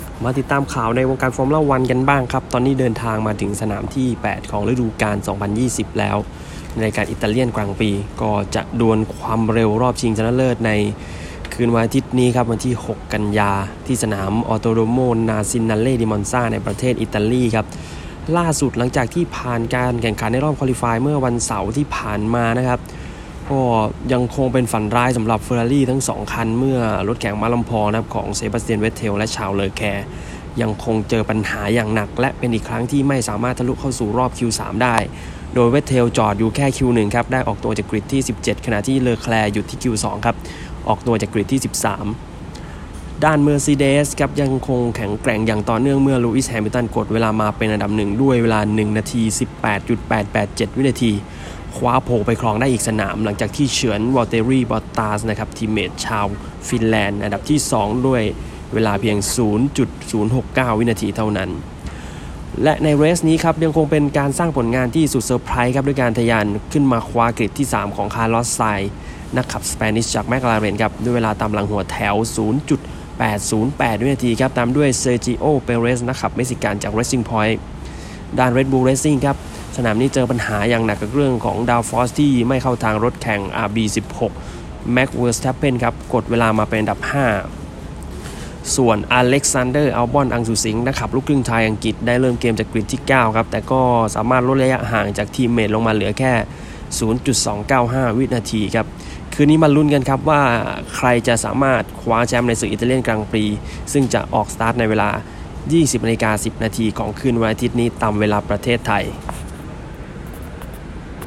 คลิกฟังข่าวหากไม่มีเวลาอ่าน